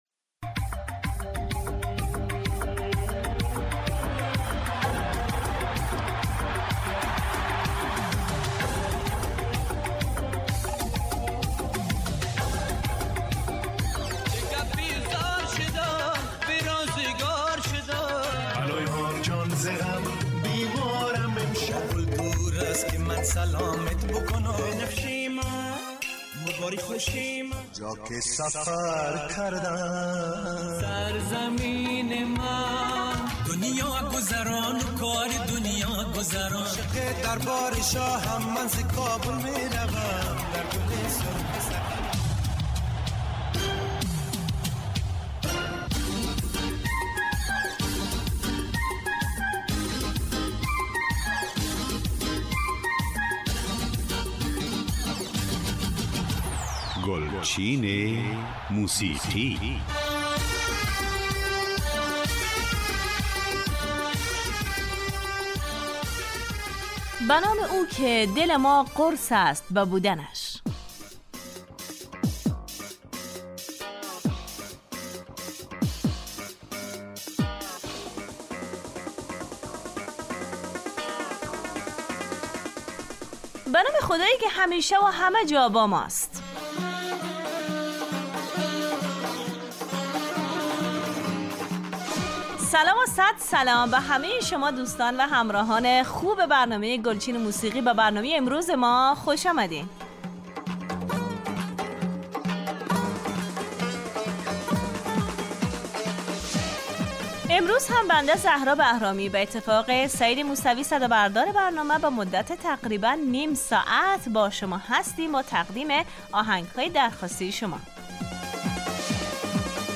برنامه ای متنوع با پخش آهنگهای جدید محلی و پاپ فارسی